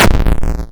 ballexplode.ogg